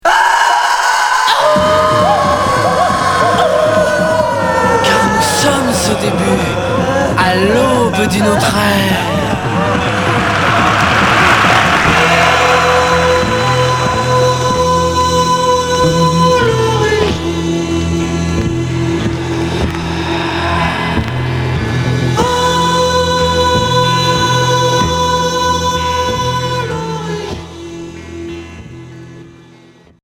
Progressif expérimental Unique 45t retour à l'accueil